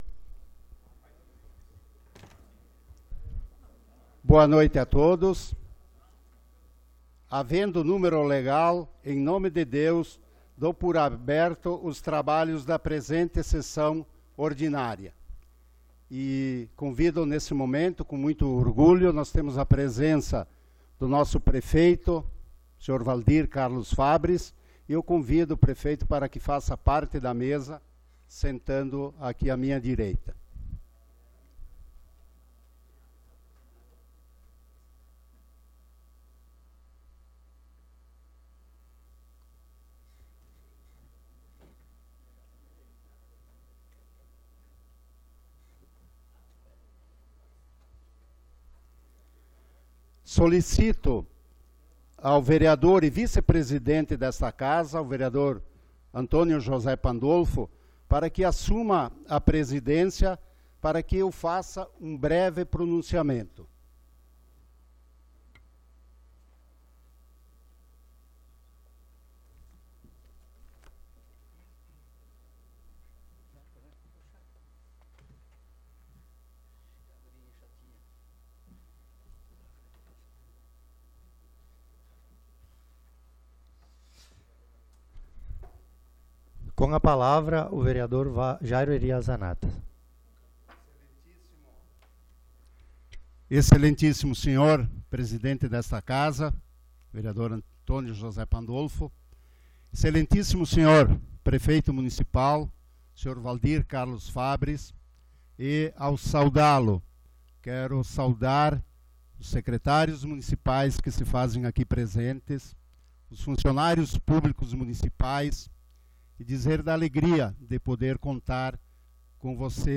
Sessão Ordinária do dia 07 de Março de 2019